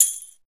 130JAMTAMB-R.wav